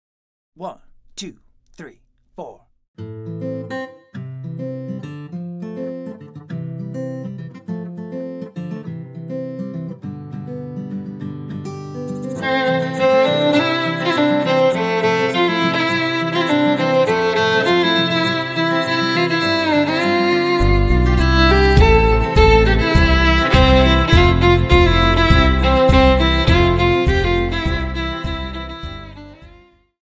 Violin
Demo